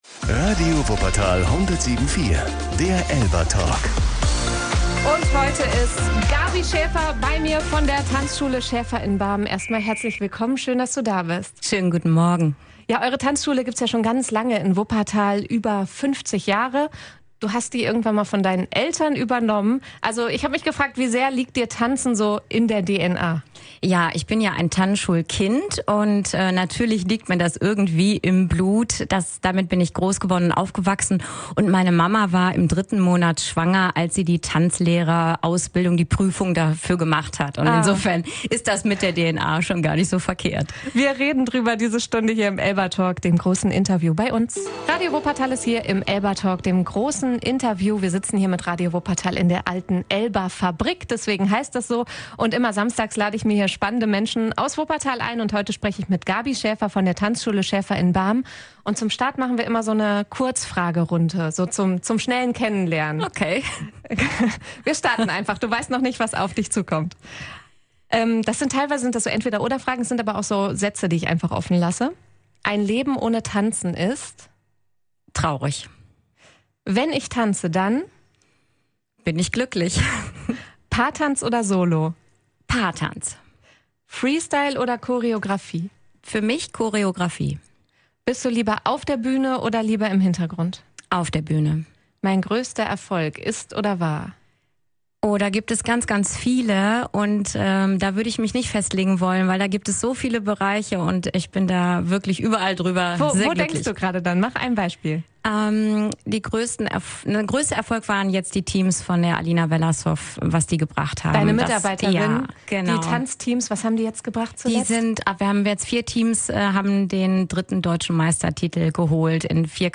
„Tanzen macht glücklich“, sagt sie im ELBA-Talk. Im Interview erzählt sie, warum Tanzen für sie mehr ist als nur Sport, und was ihr hilft, zur Ruhe zu kommen.